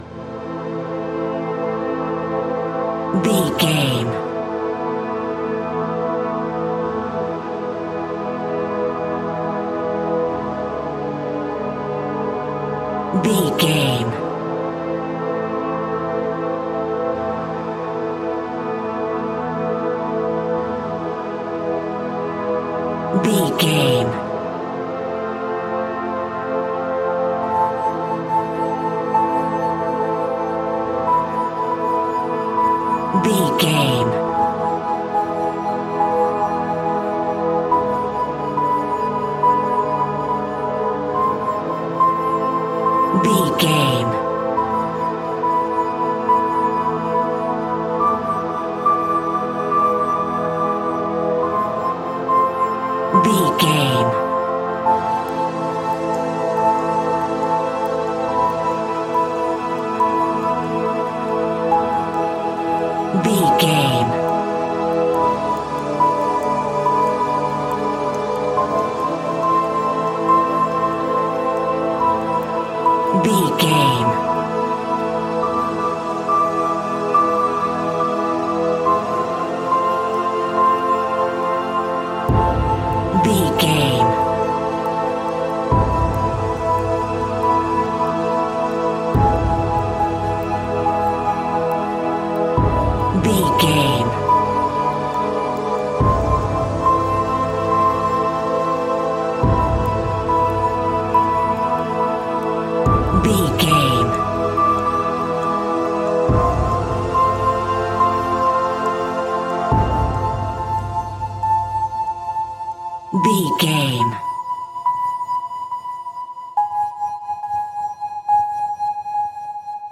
Aeolian/Minor
Slow